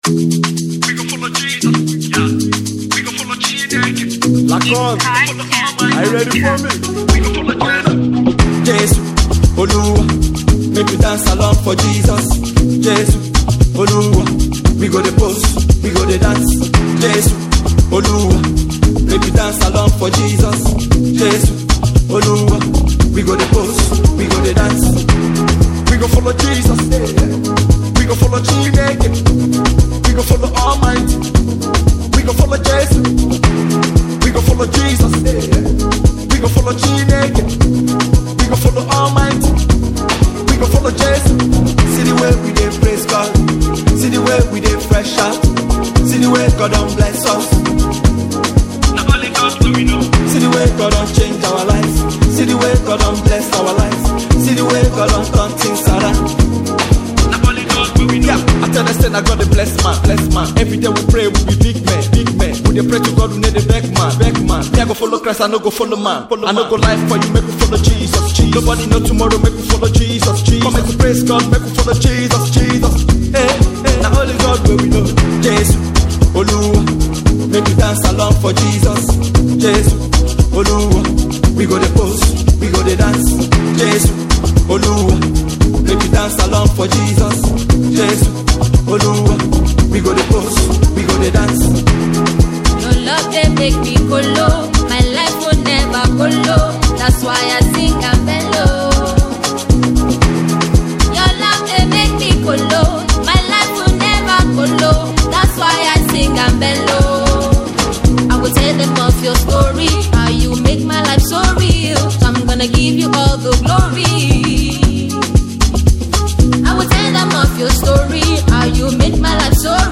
gospel song
The song is a danceable praise song.